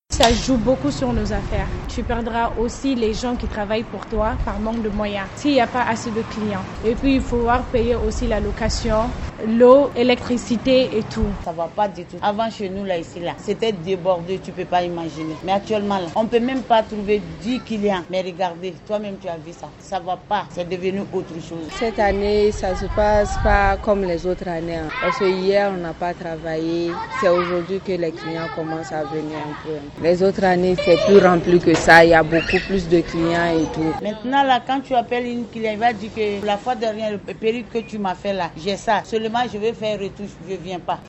Le grand marché de Bamako est bondé de monde ce lundi (19/07/21) matin. Clients et vendeurs ambulants se bousculent.